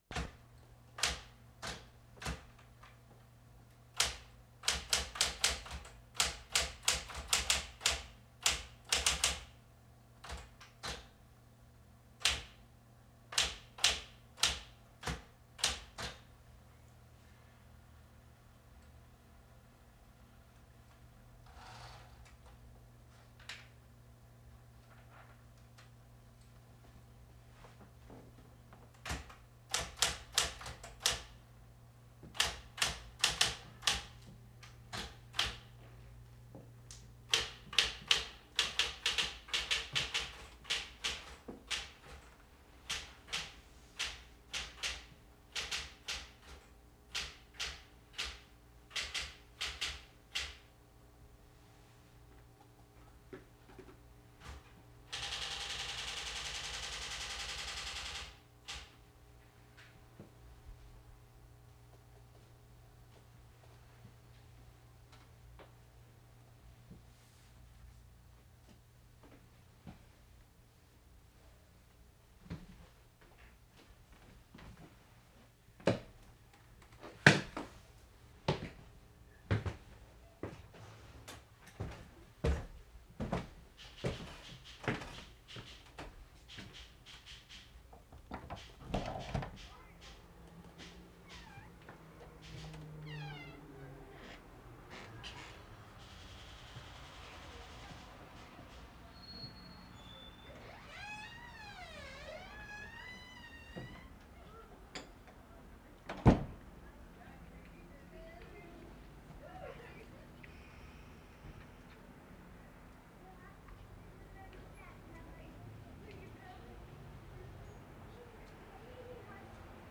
WORLD SOUNDSCAPE PROJECT TAPE LIBRARY
Domestic vignette.
l'00" typewriter tapping in recordist's home, very slow typing.
1'20" recordist leaves typewriter.
1'30" moving out the front door.
2'10" moving back in front door and upstairs.
2'45" typewriter again.